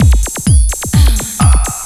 TECHNO125BPM 16.wav